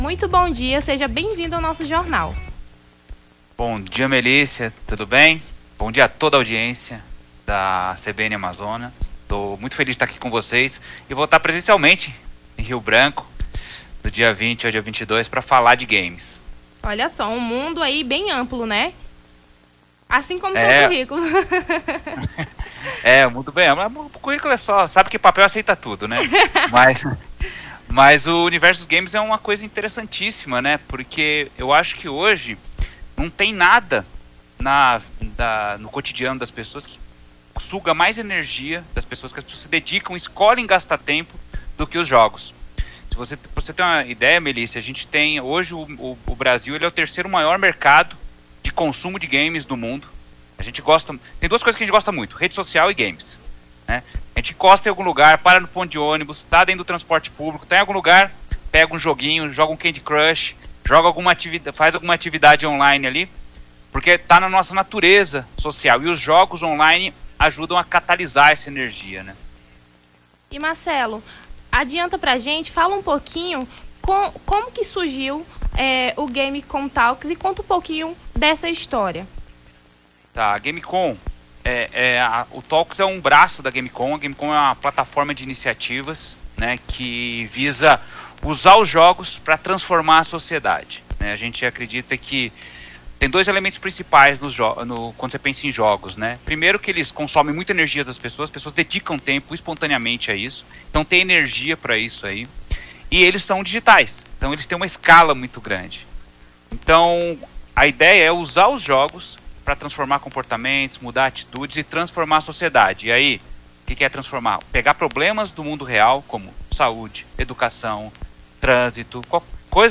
Nome do Artista - CENSURA - ENTREVISTA (GAMES TALKS) 12-09-23.mp3